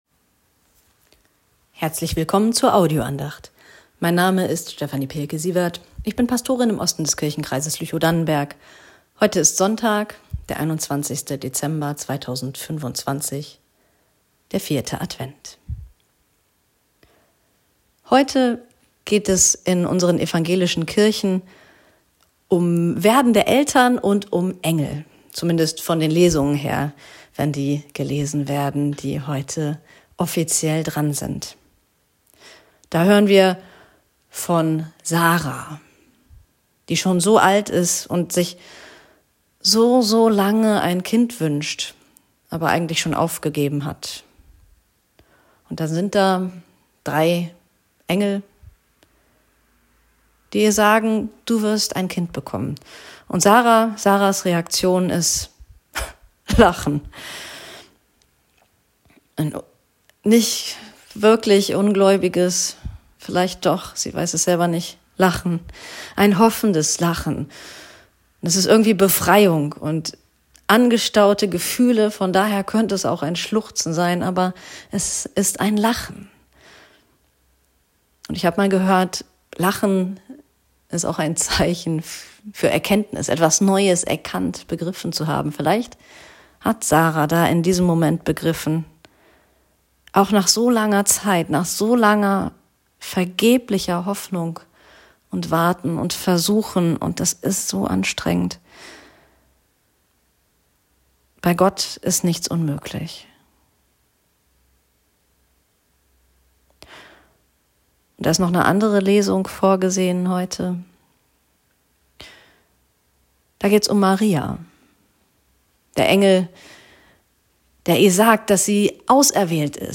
Beten und Lachen ~ Telefon-Andachten des ev.-luth.